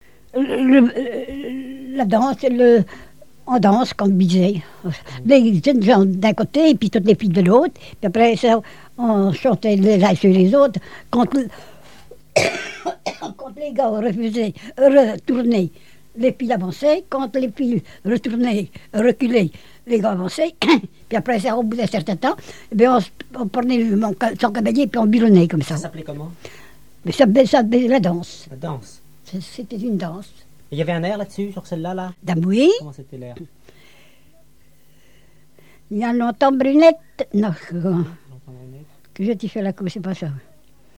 collecte du répertoire de chansons, d'airs de branles et un conte
Catégorie Témoignage